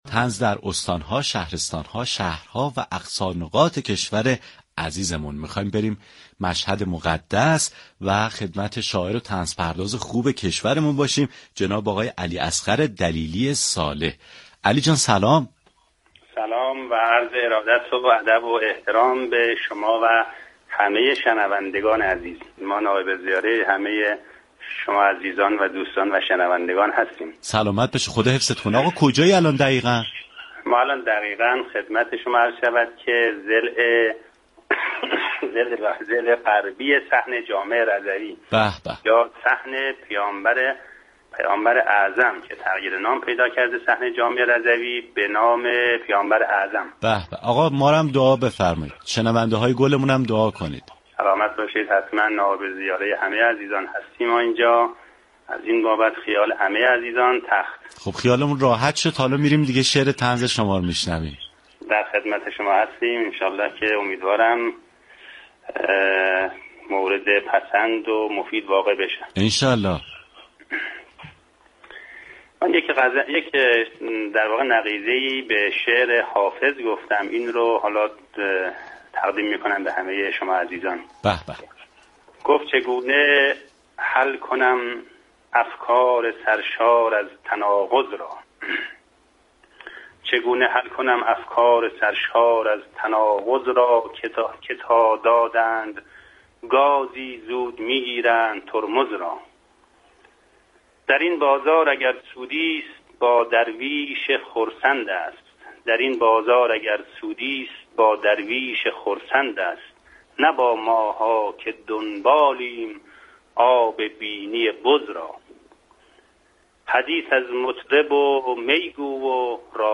اثری طنز از او با خوانش خود شاعر بشنوید.